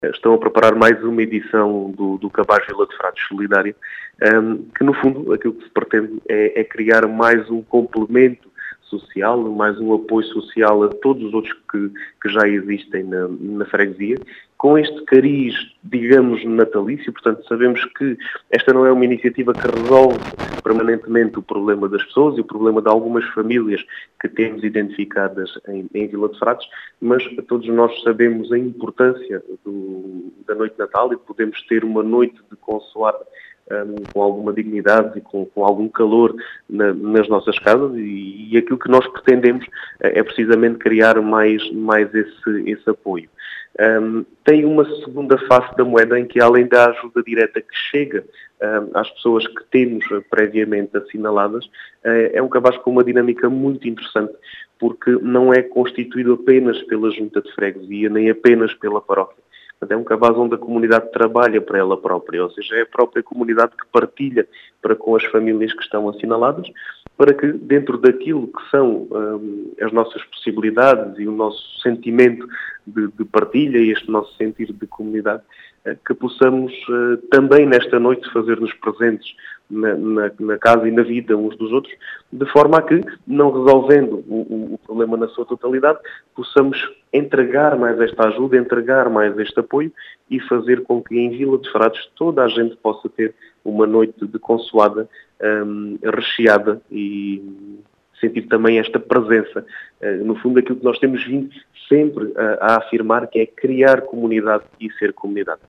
As explicações são de Diogo Conqueiro, presidente da Junta de Freguesia de Vila de Frades que quer proporcionar uma “consoada com mais qualidade” a quem mais precisa.